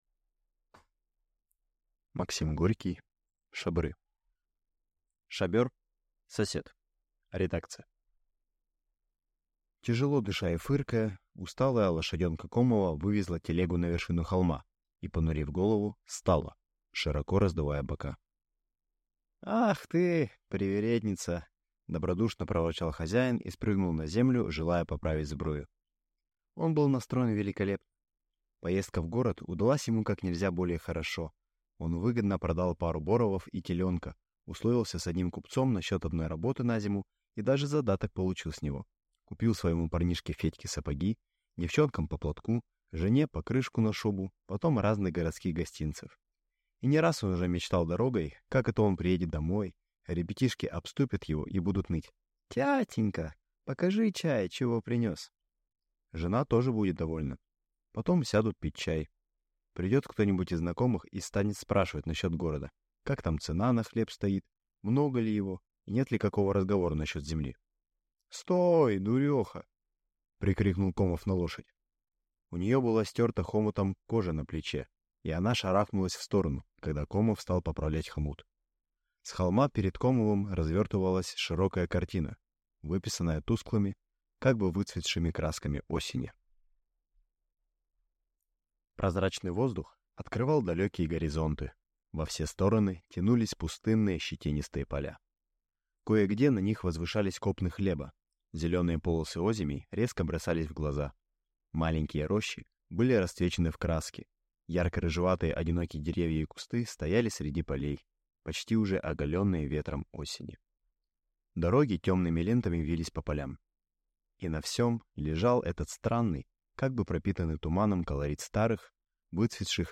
Аудиокнига Шабры | Библиотека аудиокниг